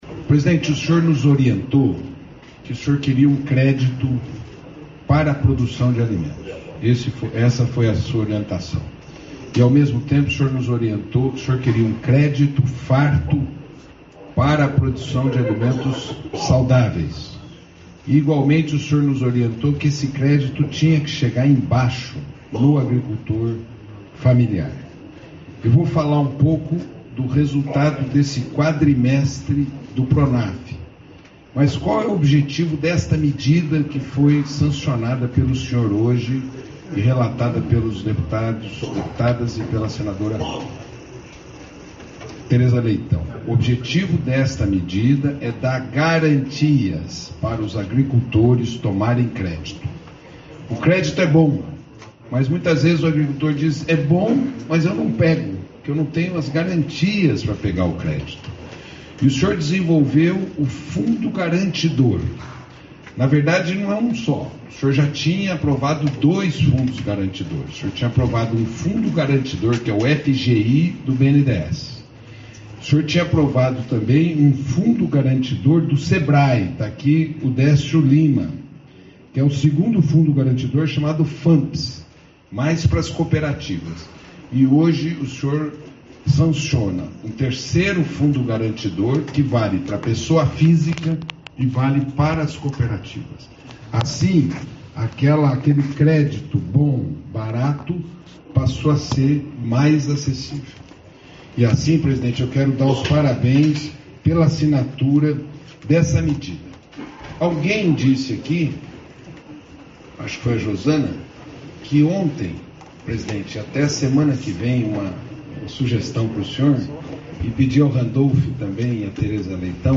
Objetivo é garantir as operações contratadas no âmbito do Programa Nacional de Fortalecimento da Agricultura Familiar (Pronaf); ouça as falas dos ministros Paulo Teixeira, do Desenvolvimento Agrário e Agricultura Familiar, e Fernando Haddad, da Fazenda, durante a sanção, nesta quarta-feira (27), no Palácio do Planalto.